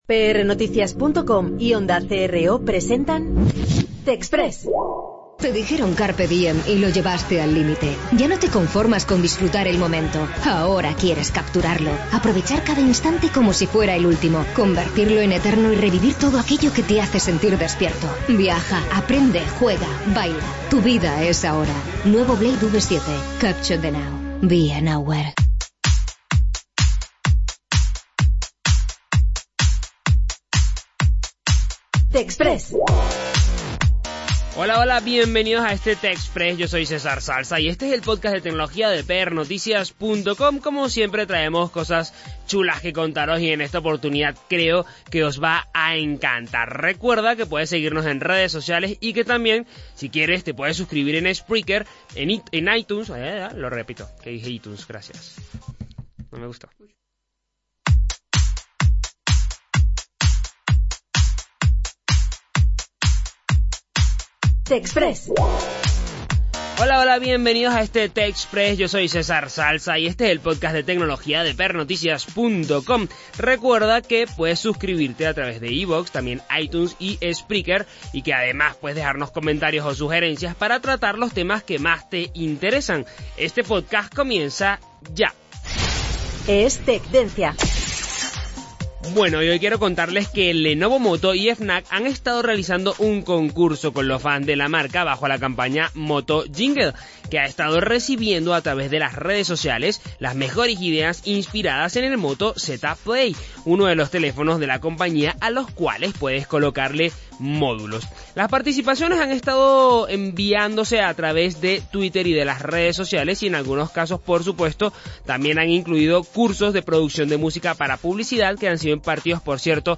Lenovo Moto y FNAC han mantenido en redes sociales una campaña-concurso para los mejores jingles inspirados en el Moto Z Play, y ya tenemos al ganador. Escucha los cuatro mejores jingles en el podcast de TechXpress especial.